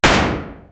GUNSHOT.mp3